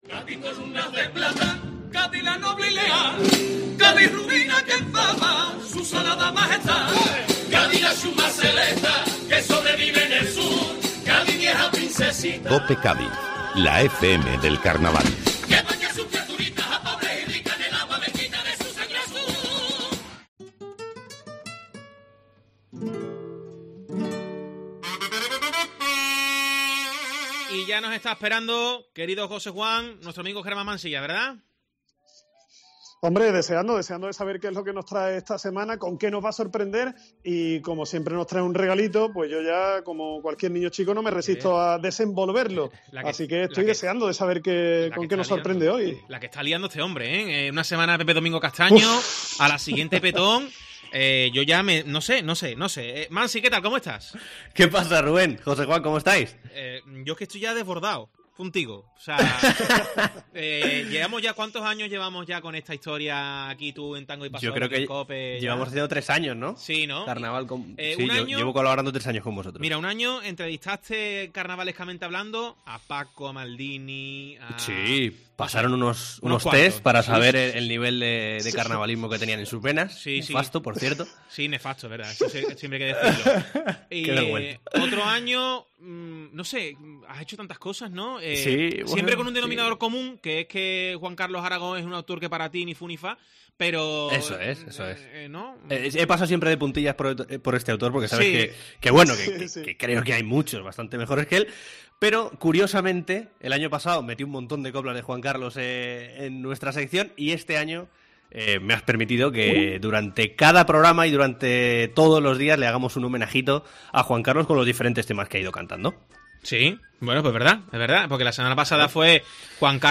chirigotero y comparsista